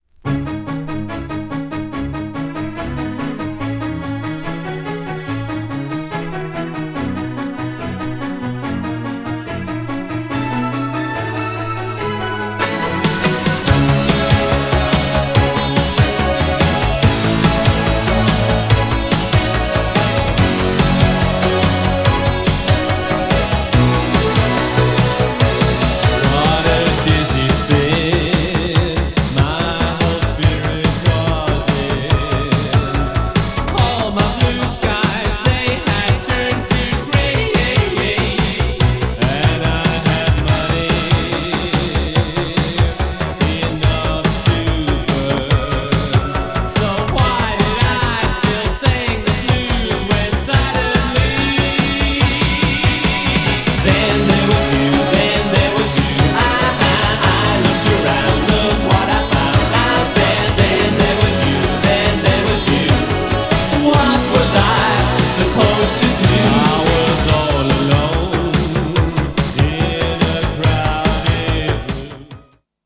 GENRE Dance Classic
BPM 131〜135BPM
EURO_DISCO
エモーショナル # キャッチー # ドラマティック